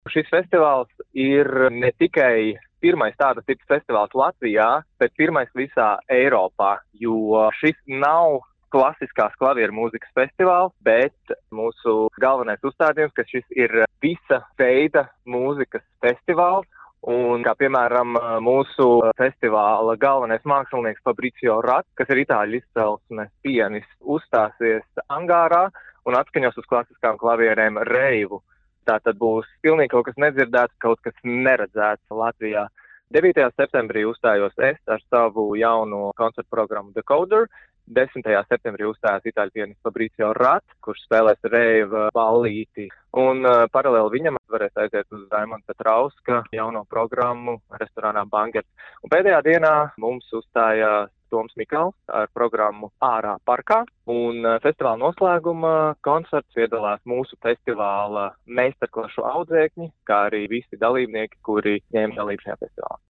RADIO SKONTO Ziņās par neparastu klaviermūzikas festivālu Kuldīgā